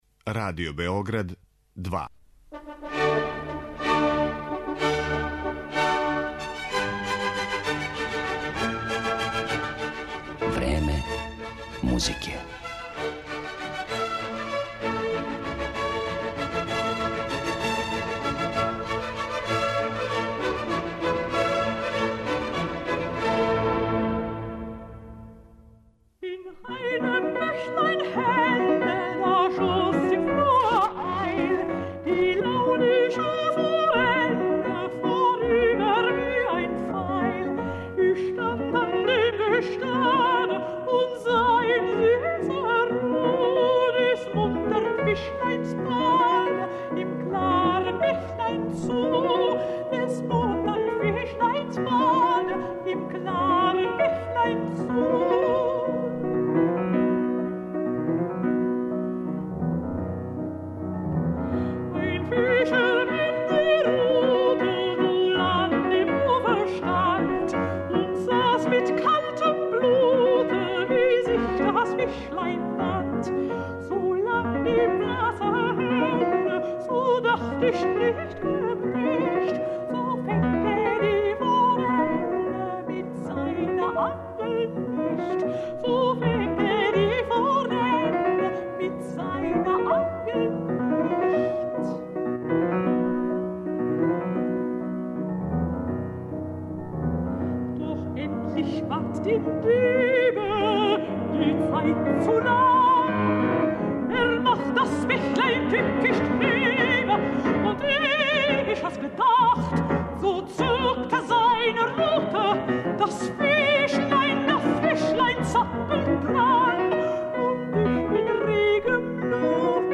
мецосопрану